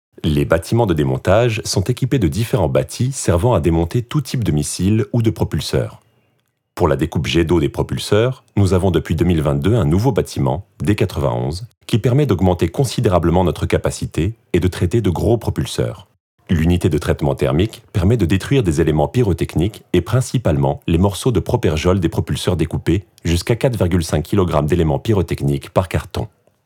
Voix-off FR film corporate